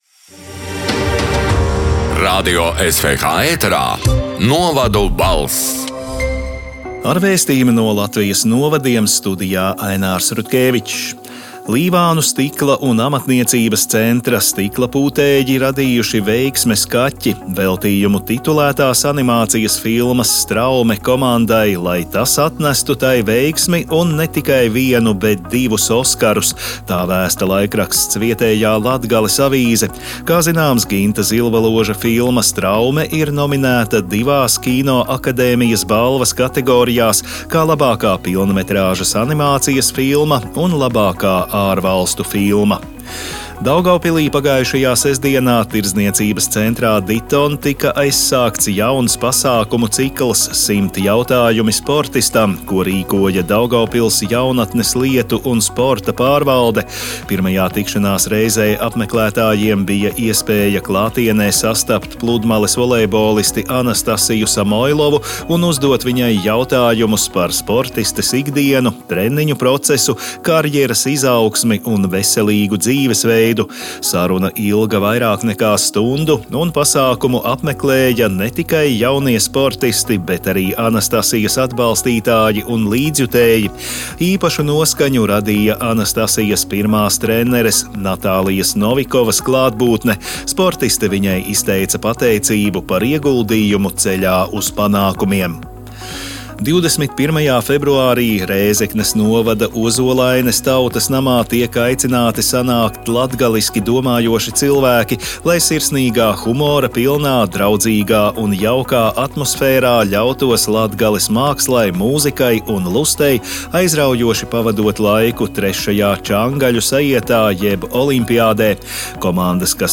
“Novadu balss” 7. februāra ziņu raidījuma ieraksts: